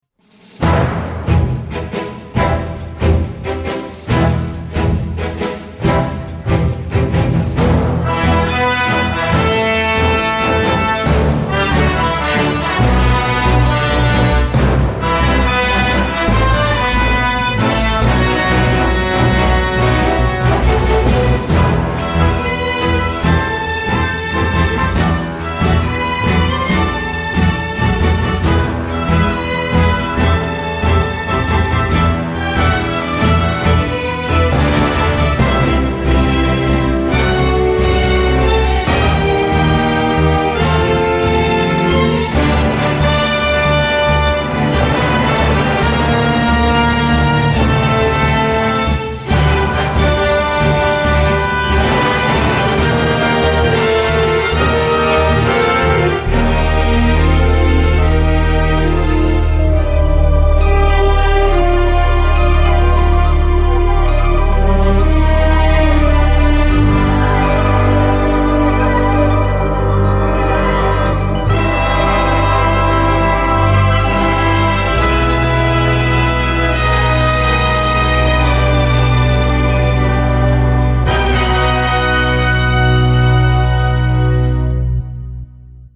toujours aussi frénétique et exubérante
chacun des morceaux est un condensé d’énergie pétillante